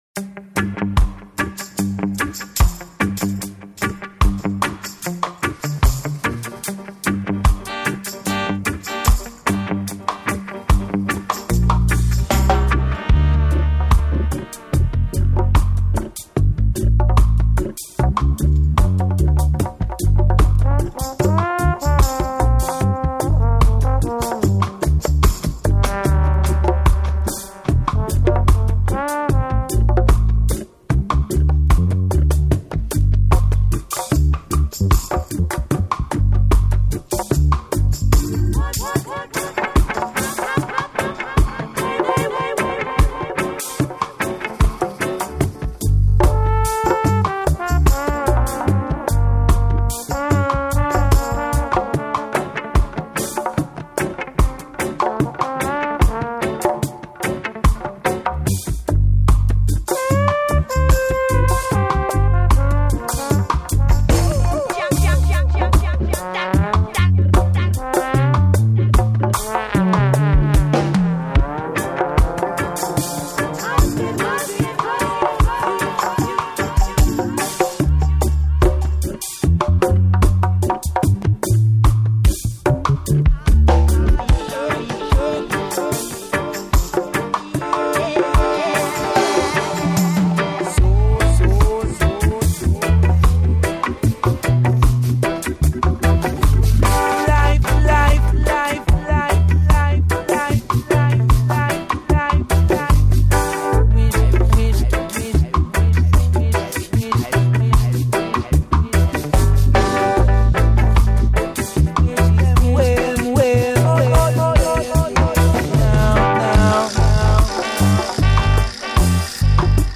est un mix exclusif
au groove irrésistible.